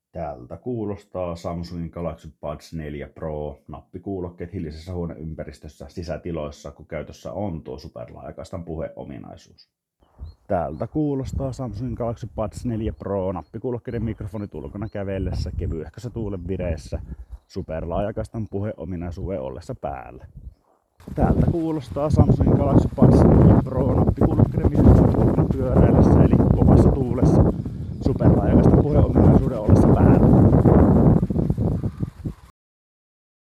Mikrofonin ääniesimerkki Superlaajakaistan puhe -ominaisuuden kanssa
Superlaajakaistan puhe -tila selkeyttää asetusruudun mukaan ääntä 16 kHz:n kaistanleveydellä. Käytännössä ääni vaikuttaisi pykälän selkeämmältä ja suurimpana erona sisätilan ääninäyte muuttui huomattavan laadukkaaksi hävittäen jostain syystä huoneen kaikujakin voimakkaammin. Ulkoilmassa tuulen ääniä tila ei kuitenkaan poista, vaan pyöräillessä taustahumina on edelleen huomattavan voimakasta.
Samsung-Galaxy-Buds4-Pro-mikrofonit-Superlaajakaistan-puhe.mp3